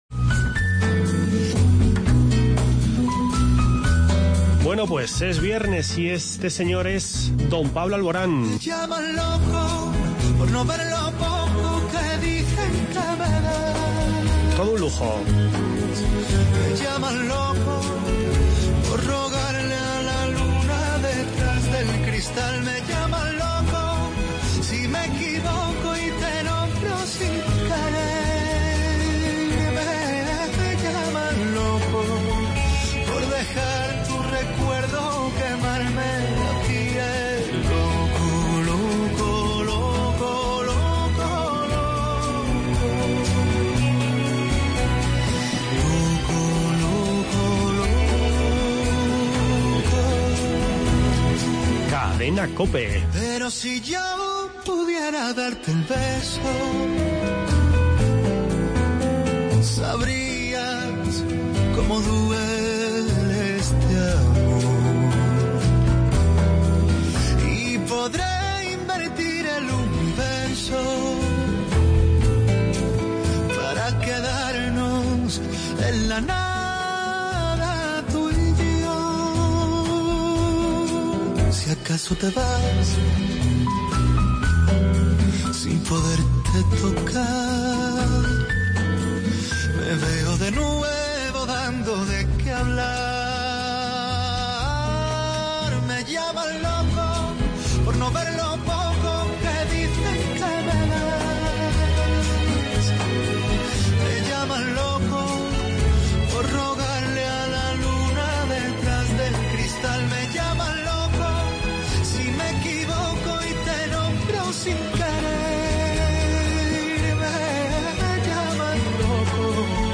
Ambos han subrayado la calidad de los vinos de Castilla-La Mancha y han incidido en la importancia de que el vino manchego llegue a los mercados de EEUU. Por su parte, el consejero de Agricultura, Francisco Martínez Arroyo, ha explicado esta mañana, en comparecencia de prensa, que acoge con "satisfacción" la decisión de las denominación de origen de unirse en una única entidad.